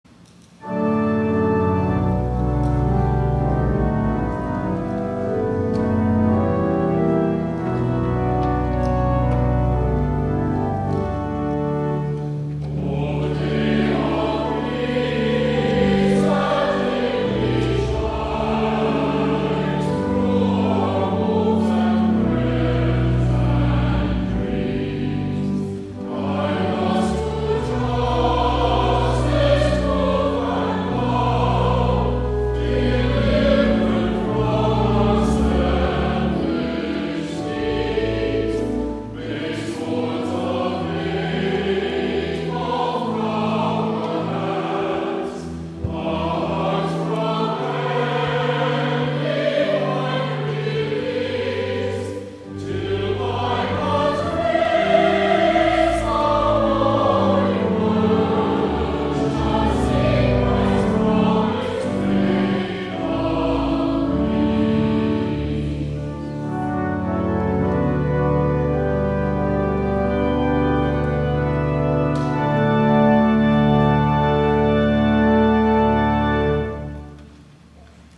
11 A.M. WORSHIP
*THE CHORAL RESPONSE